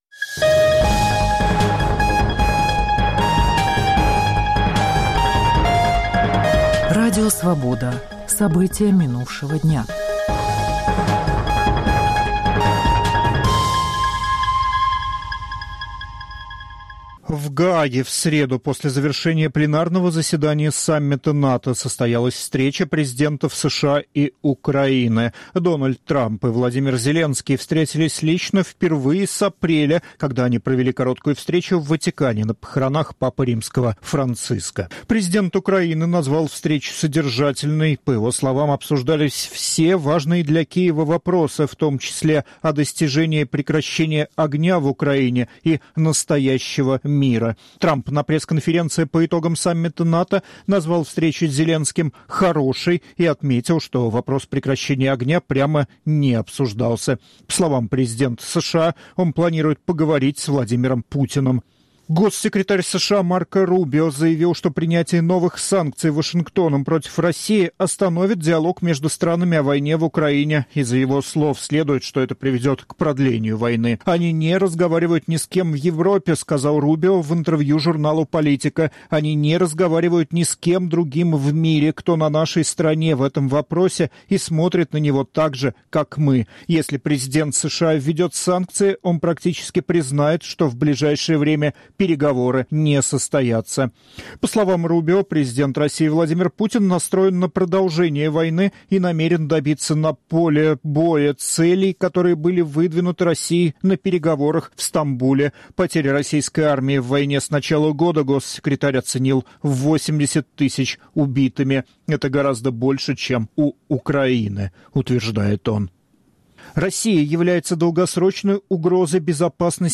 Аудионовости
Новости Радио Свобода: итоговый выпуск